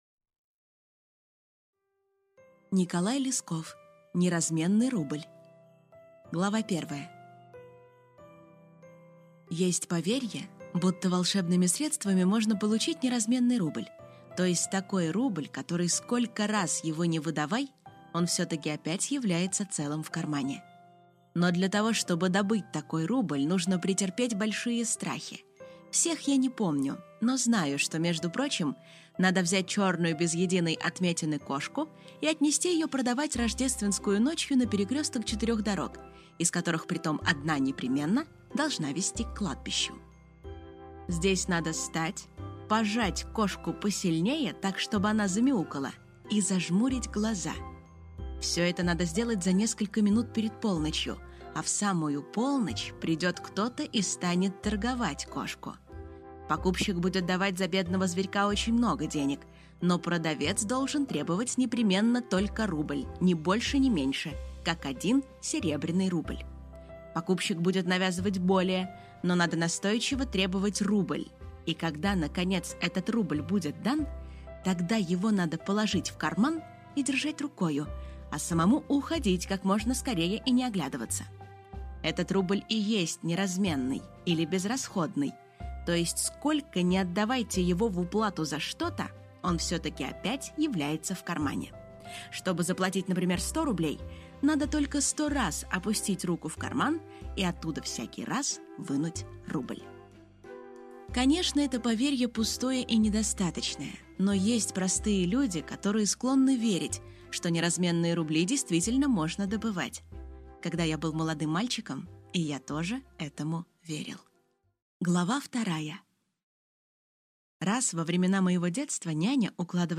Аудиокнига Неразменный рубль | Библиотека аудиокниг